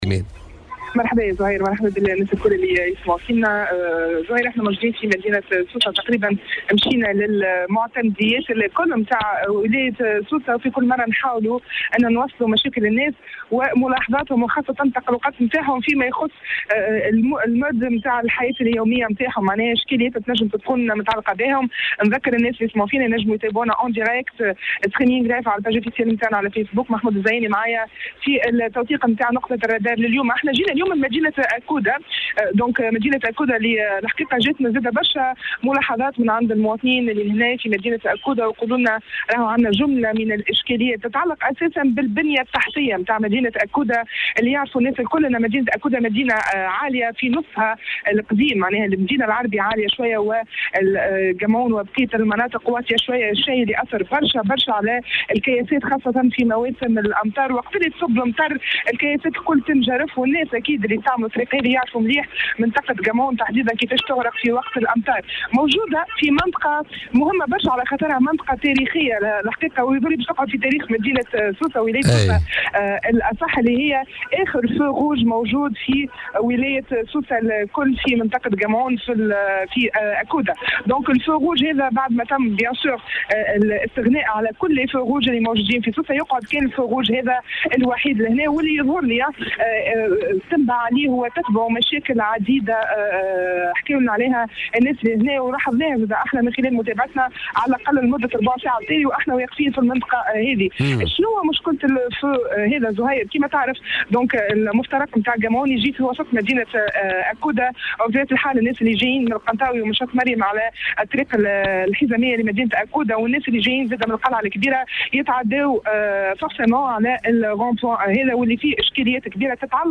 توجّه فريق الرادار اليوم الخميس إلى مدينة أكودة من ولاية سوسة وبالتحديد إلى مفترق قمعون أين عاين اخلالات وتجاوزات مرورية.